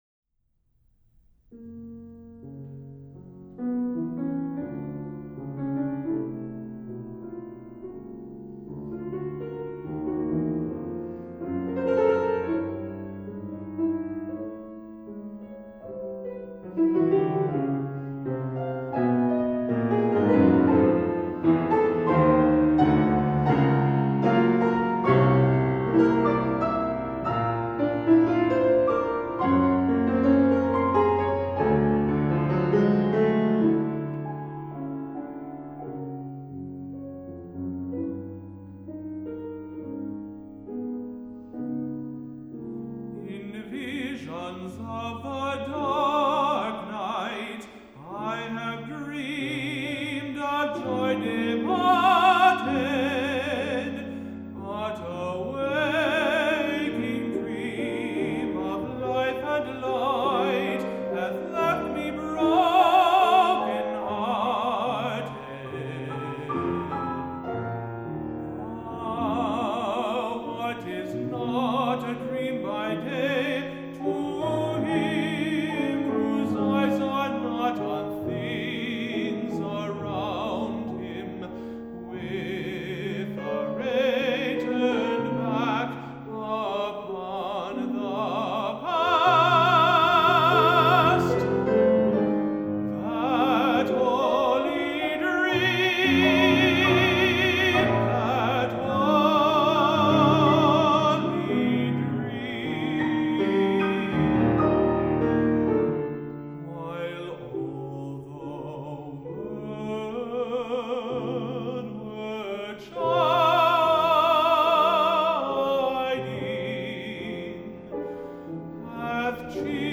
Three Songs for High Voice and Piano
tenor
piano